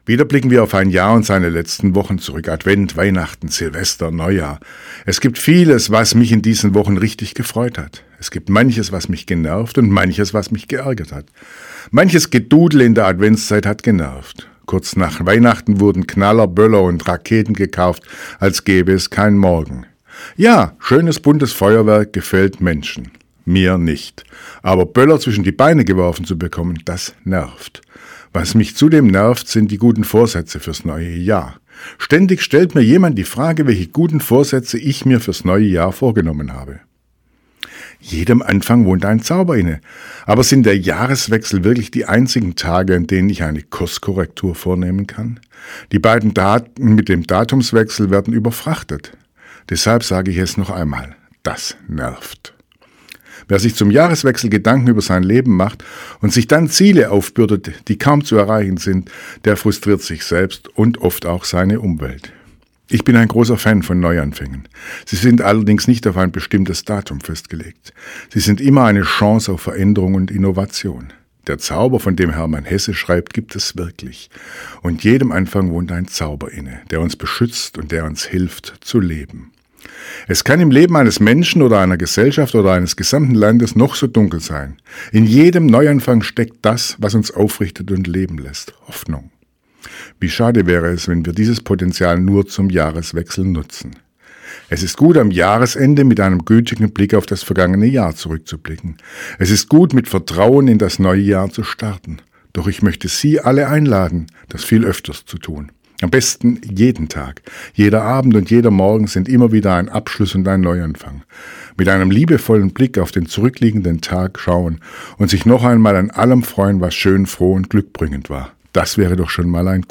Radioandacht vom 2. Januar